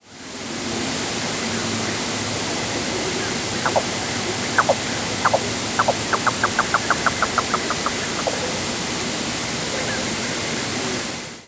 presumed Yellow-billed Cuckoo nocturnal flight calls
"Cauw" series with Chuck-will's-widow and Swainson's Thrush in the background.